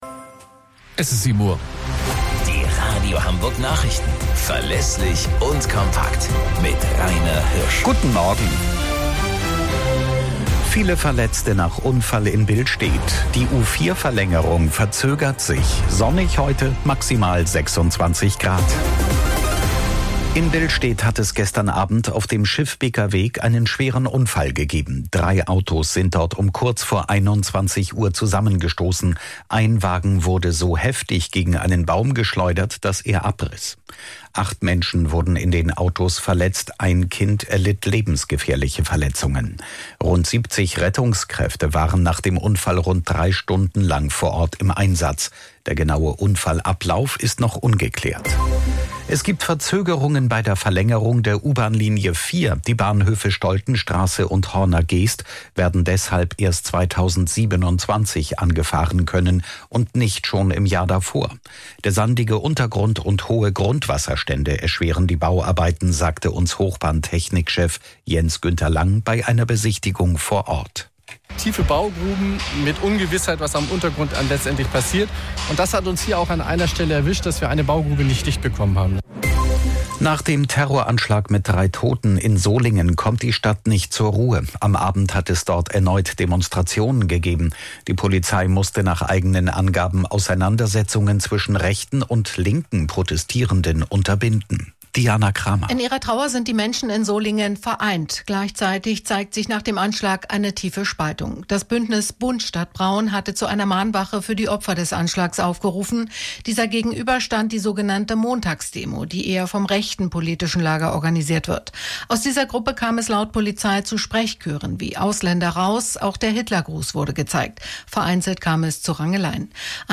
Radio Hamburg Nachrichten vom 20.09.2024 um 22 Uhr - 20.09.2024